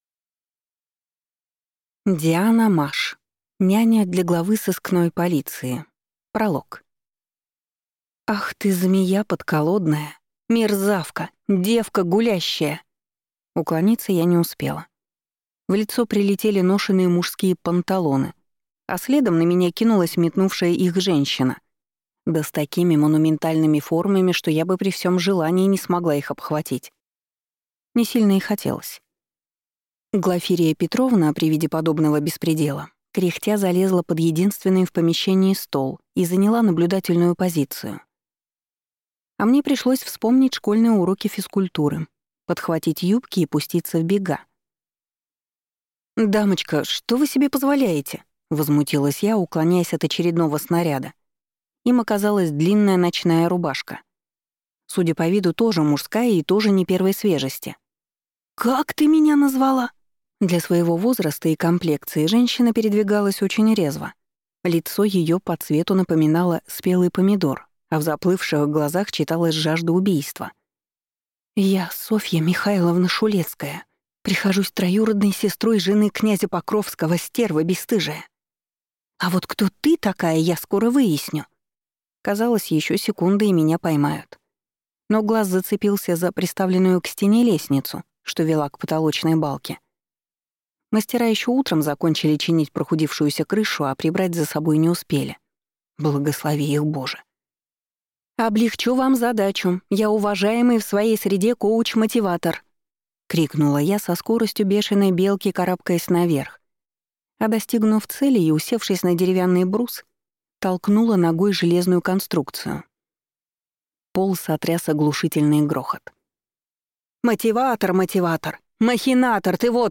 Аудиокнига Няня для главы сыскной полиции | Библиотека аудиокниг